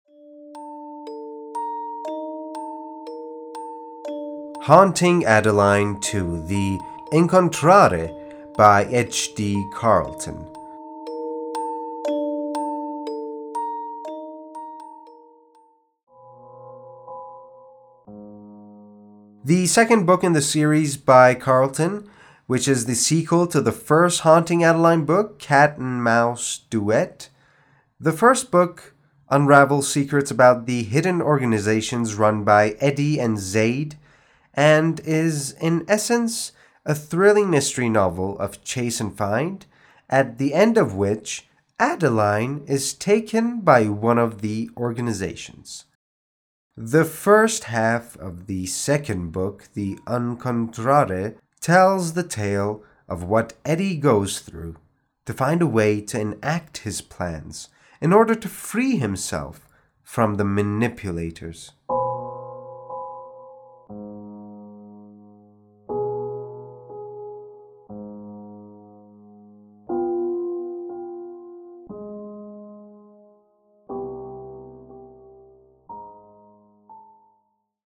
معرفی صوتی Haunting Adeline 2